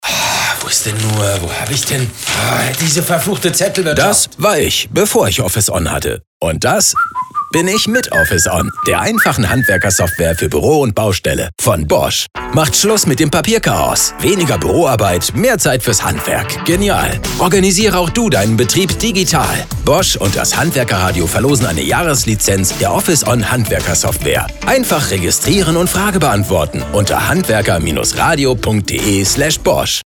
Single Spot
Bosch_Single-Spot_Beispiel.mp3